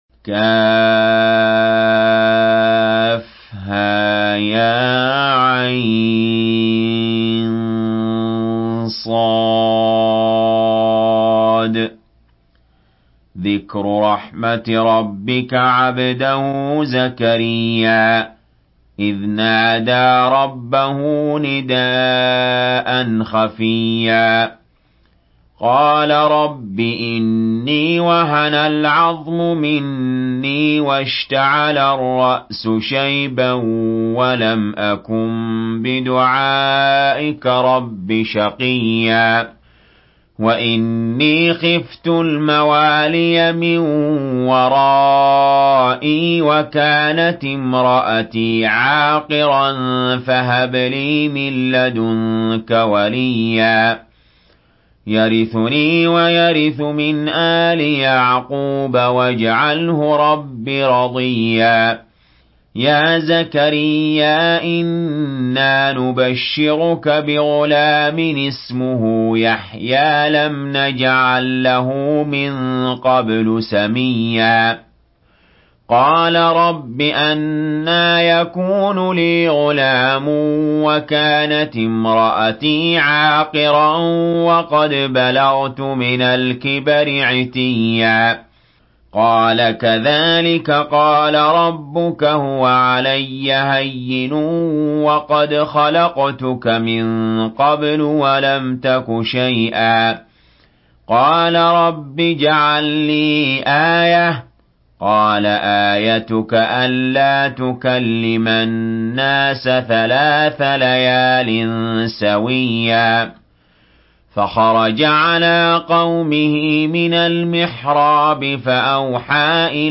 Surah مريم MP3 by علي جابر in حفص عن عاصم narration.
مرتل حفص عن عاصم